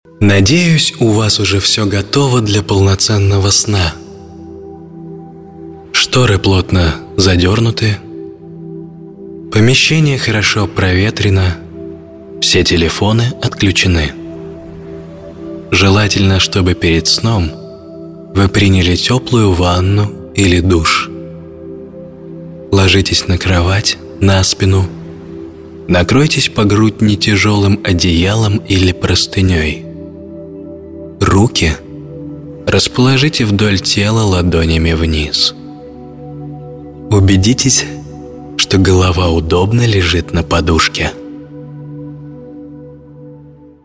Медитация
Муж, Другая/Средний